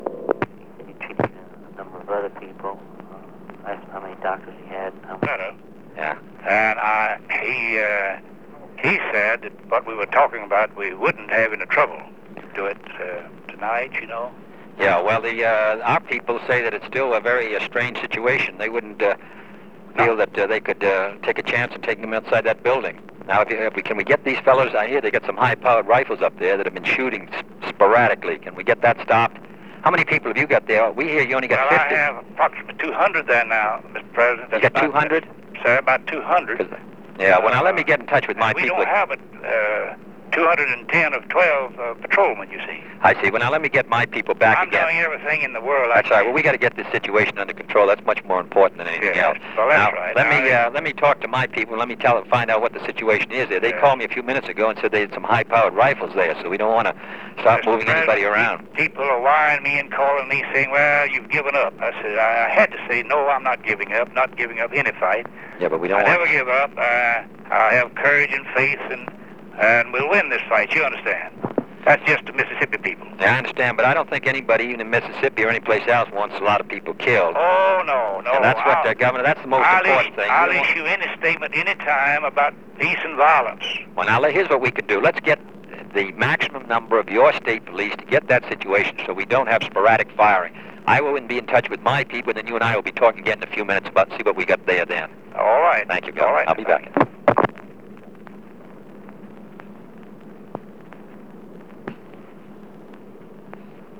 Conversation with Ross Barnett (1:45 am)
Secret White House Tapes | John F. Kennedy Presidency Conversation with Ross Barnett (1:45 am) Rewind 10 seconds Play/Pause Fast-forward 10 seconds 0:00 Download audio Previous Meetings: Tape 121/A57.